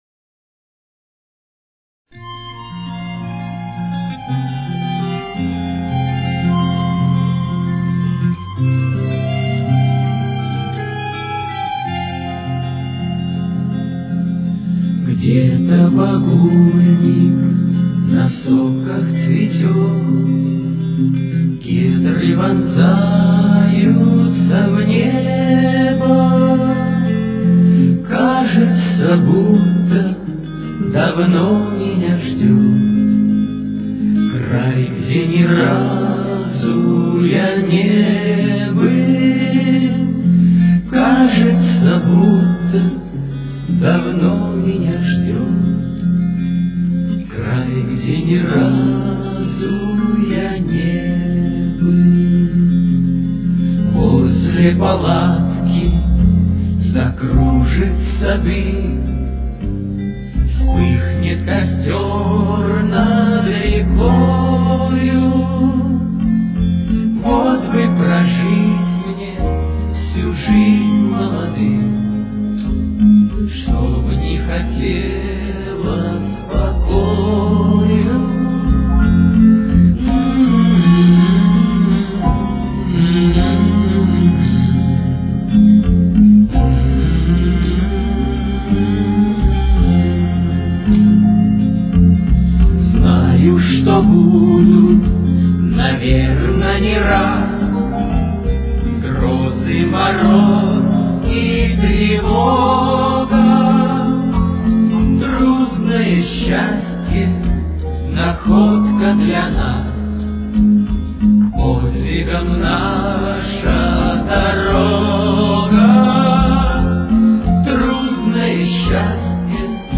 с очень низким качеством (16 – 32 кБит/с)
Тональность: Фа минор. Темп: 57.